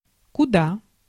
Ääntäminen
Vaihtoehtoiset kirjoitusmuodot (murteellinen) куды́ Ääntäminen : IPA: [kʊ.ˈda] Haettu sana löytyi näillä lähdekielillä: venäjä Käännös Ääninäyte 1. où {m} France (Paris) Translitterointi: kuda.